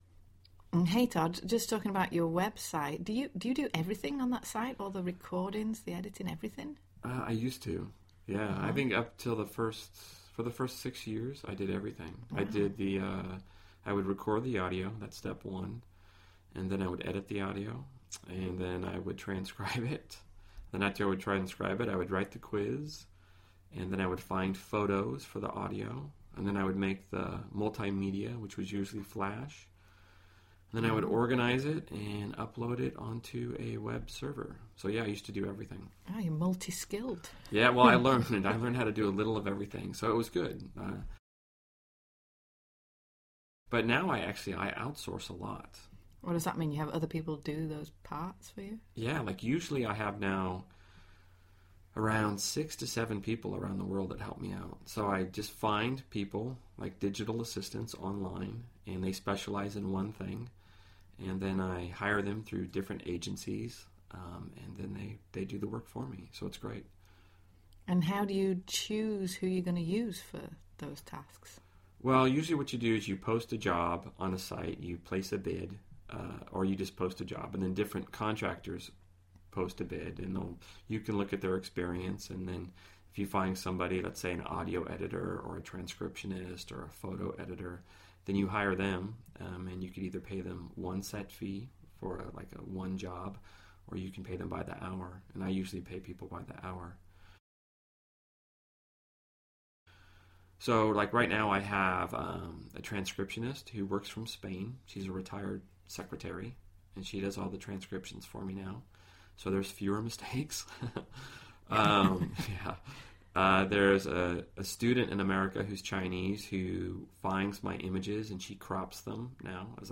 英文原版对话1000个:1245 Outsourcing 听力文件下载—在线英语听力室
在线英语听力室英文原版对话1000个:1245 Outsourcing的听力文件下载,原版英语对话1000个,英语对话,美音英语对话-在线英语听力室